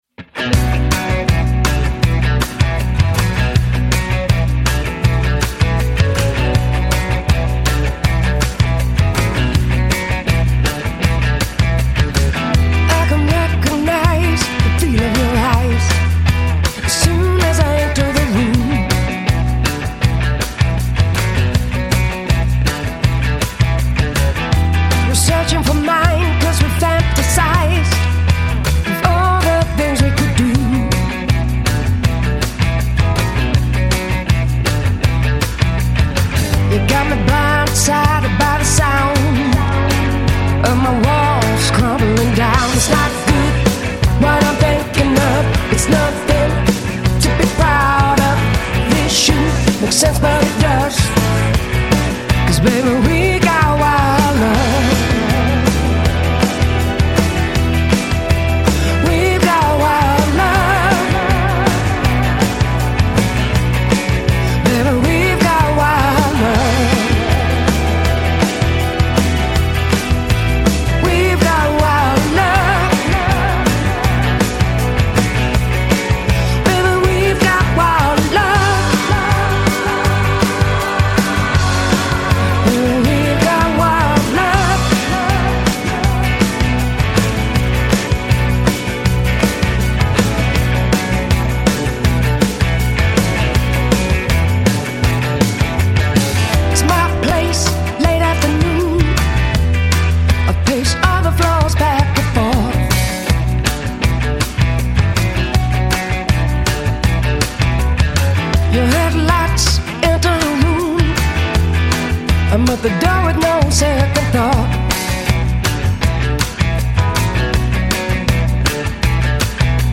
Жанр: Blues